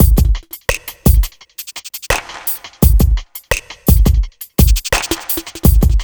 1TI85BEAT1-L.wav